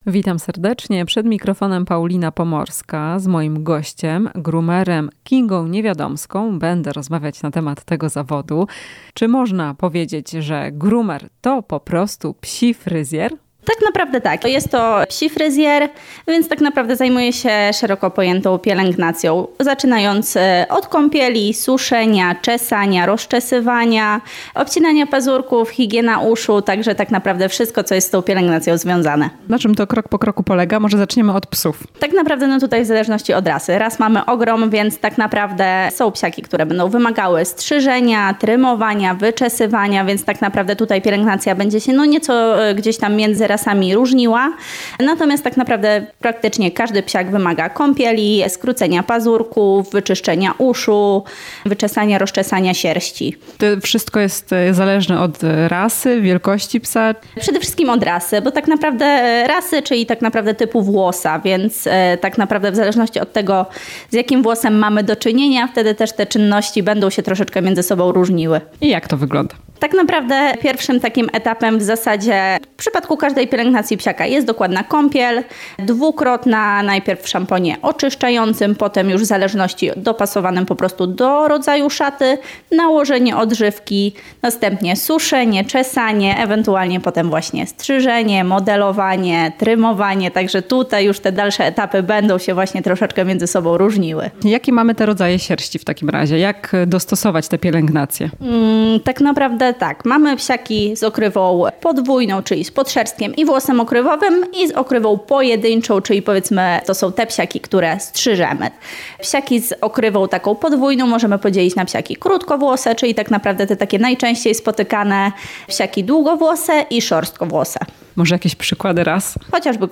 W "Chwili dla pupila" wyjaśniamy czym zajmuje się psi fryzjer i jakie są rodzaje sierści. Rozmowa